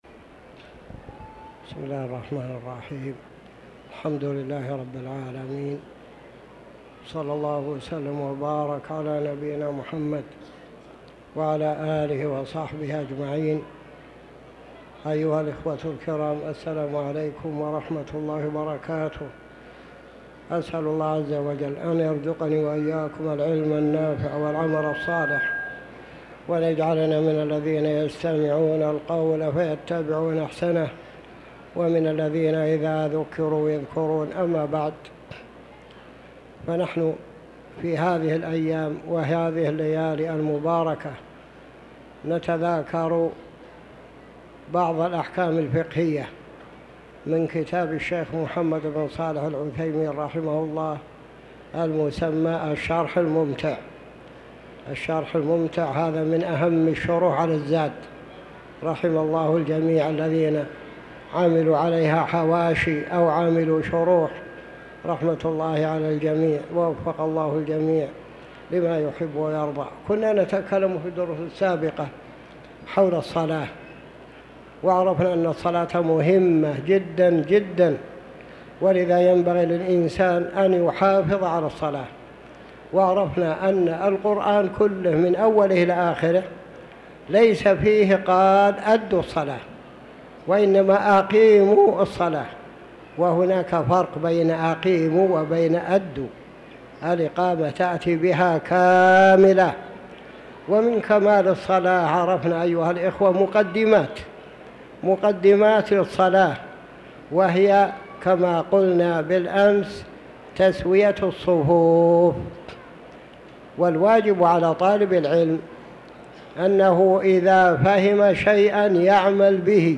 تاريخ النشر ٢٧ شوال ١٤٤٠ هـ المكان: المسجد الحرام الشيخ